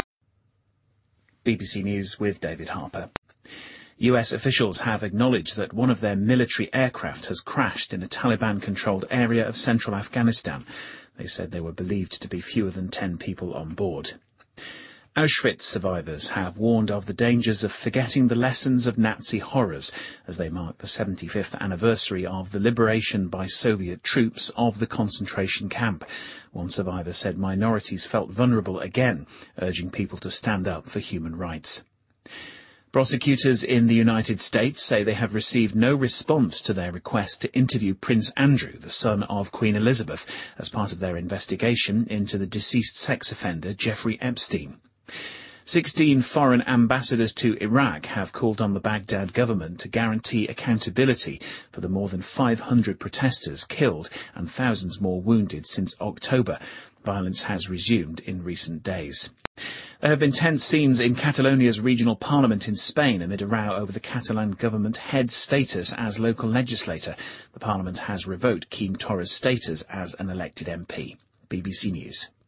英音听力讲解:奥斯威辛集中营解放75周年